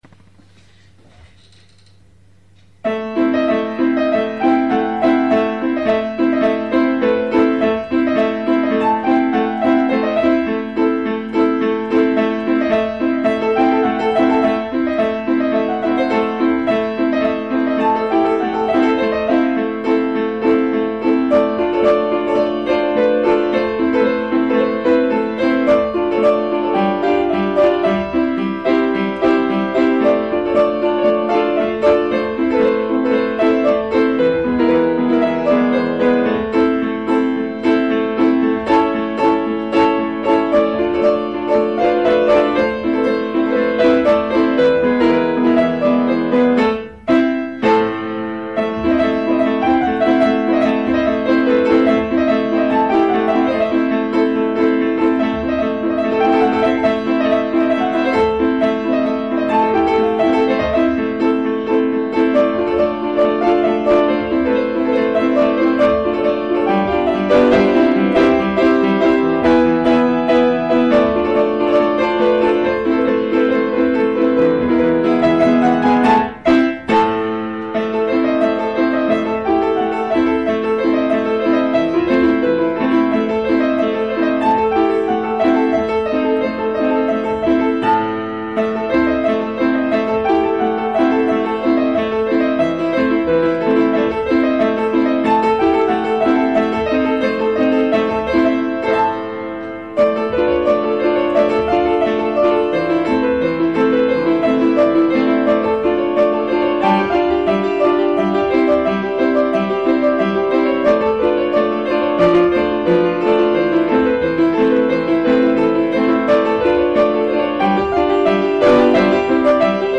אחלה קצב.
נשמע ישראלי.
ממש יפה! קופצני ושמח!!!